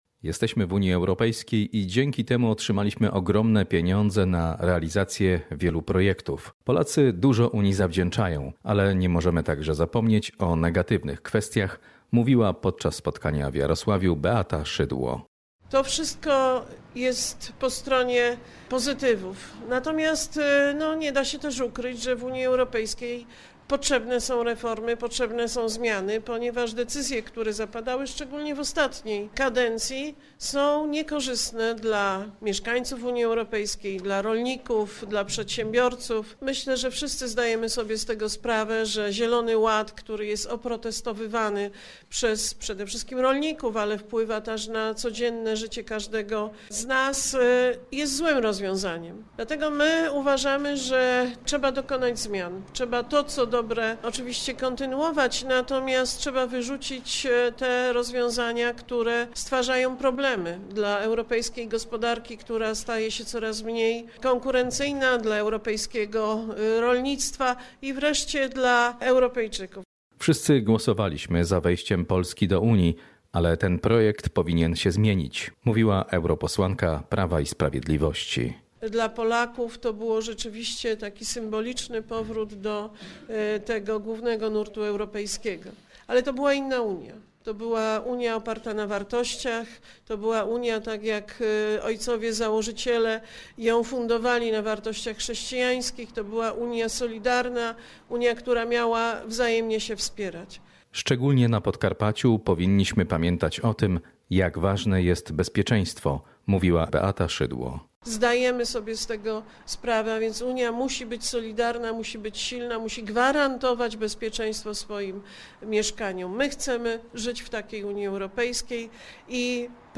Zielony ład i bezpieczeństwo Polski – to główne tematy konferencji europoseł Beaty Szydło, która odwiedziła Podkarpacie. W Jarosławiu, podczas konferencji mówiła, że zbliżające się wybory do Parlamentu Europejskiego, zdecydują o przyszłości Polski.
Relacja